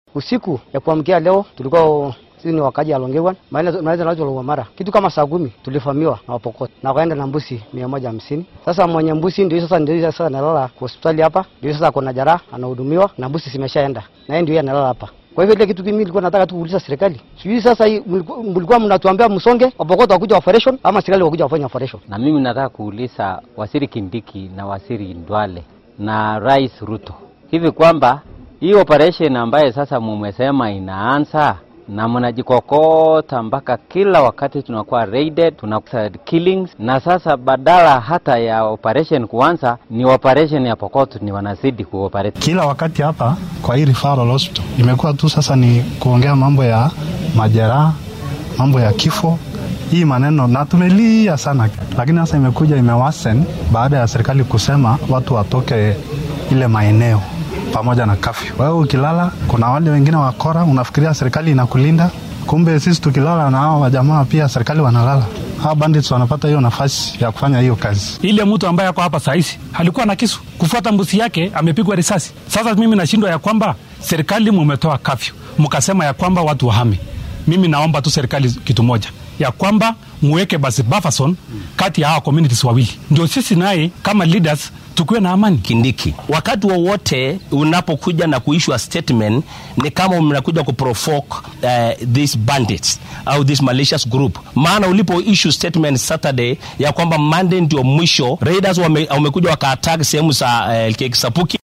Weerarkan ayay burcadda gaysteen xilli halkaasi la geeyay ciidamada difaaca dalka ee KDF-ta, sidoo kalena la billaabay howlgallo amni oo ka dhan ah burcadda. Qaar ka mid ah dadka deegaanka ee xoolaha laga dhacay ayaa warbaahinta la hadlay.